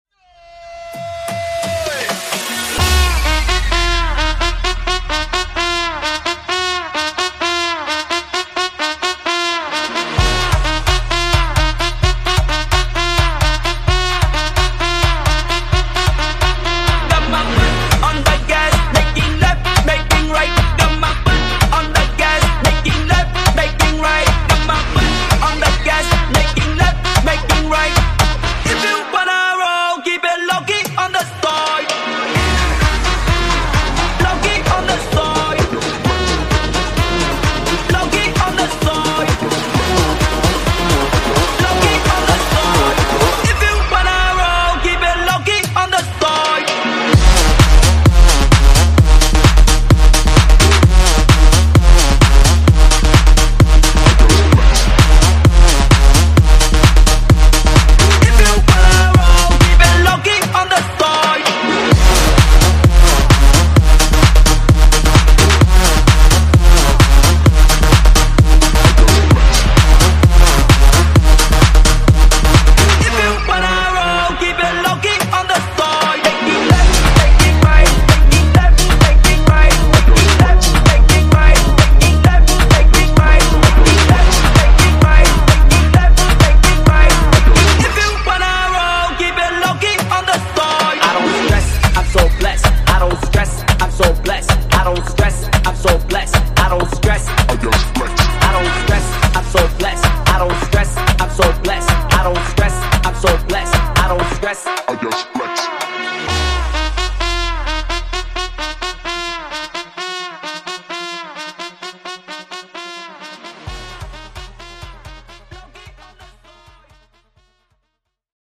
Genres: R & B , RE-DRUM
Clean BPM: 103 Time